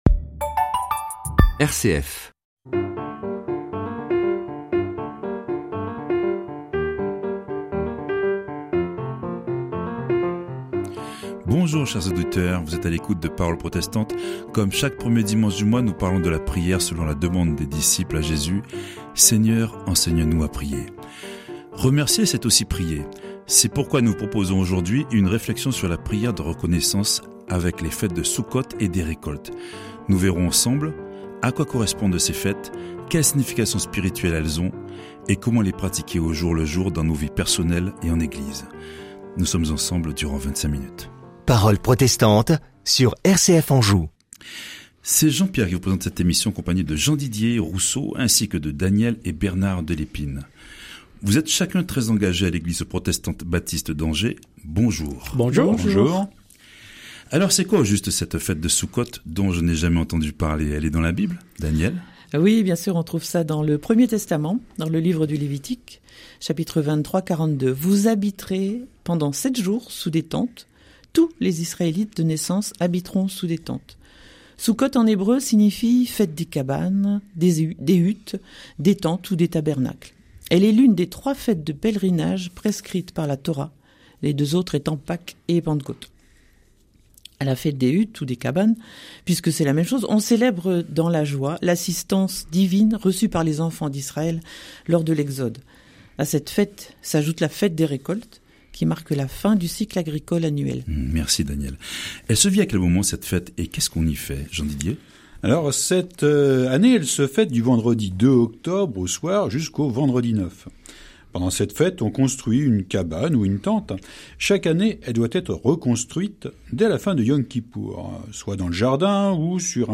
Séries : Emission de radio RCF – Parole Protestante | A l’occasion de l’enregistrement quelqu’un nous disait que c’était les juifs et non les chrétiens qui célébraient ces fêtes.